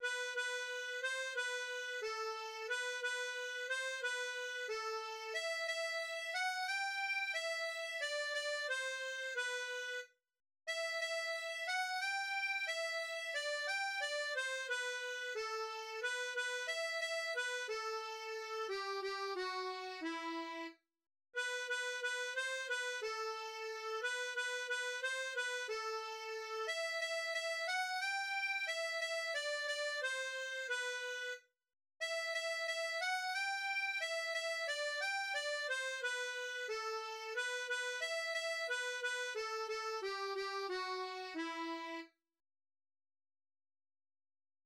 Műfaj egyéb magyar népies dal
Hangfaj moll
A kotta hangneme É-moll
Az Eger városa, papok városa egy ismeretlen eredetű mulatós dal.